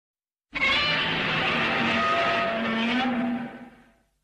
Gorgonzilla Screaming (Godzilla Roar sound effects free download